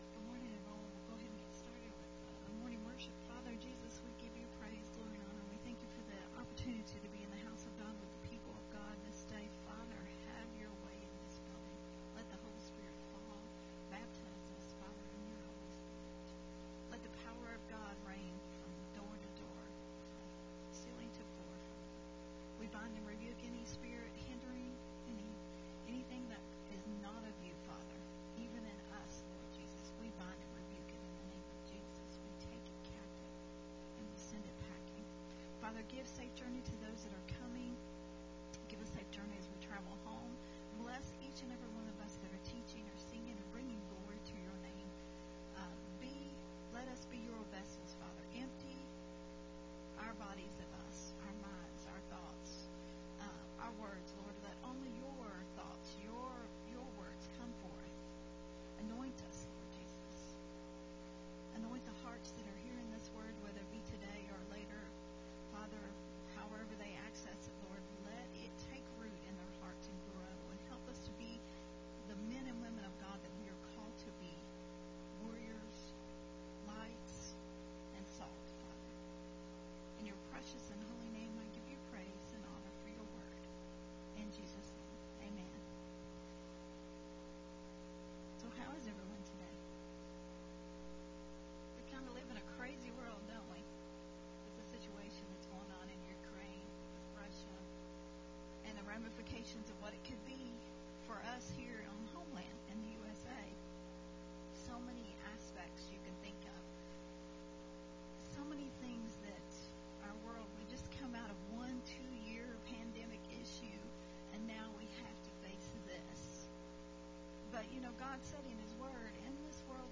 a teaching